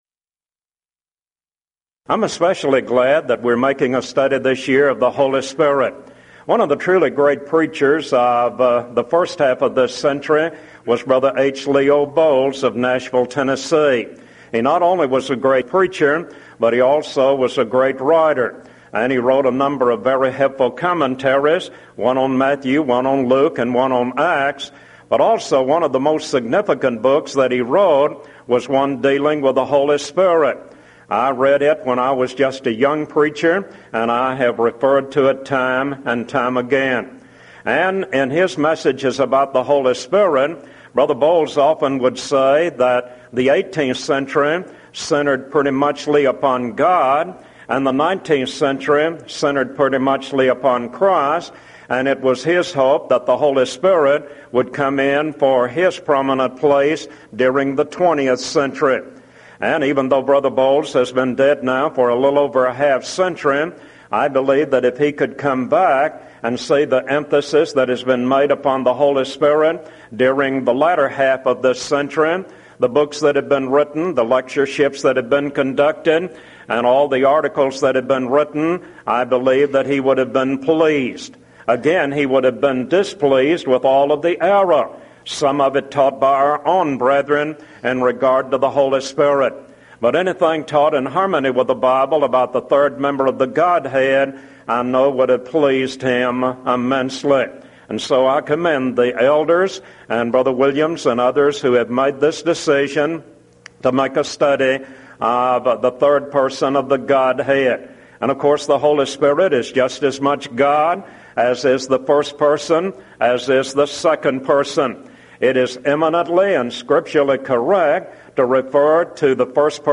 Event: 1997 Mid-West Lectures